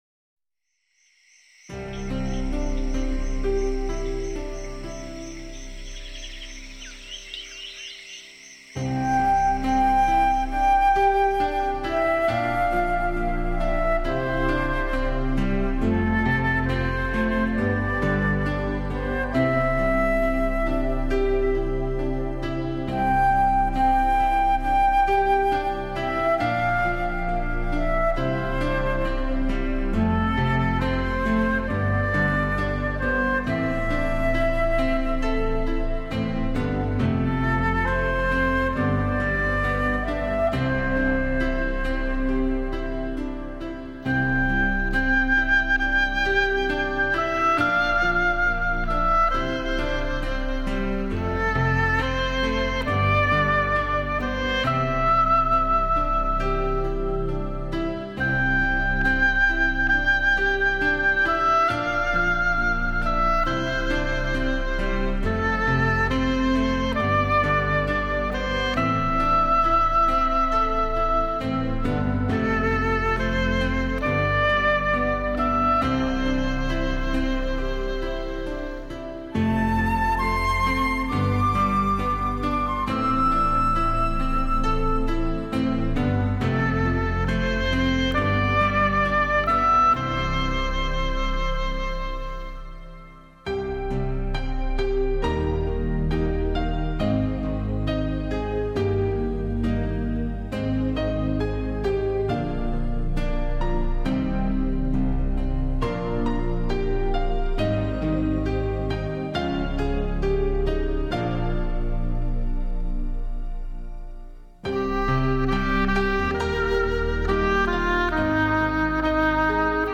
新世纪
空灵飘淼的音乐世界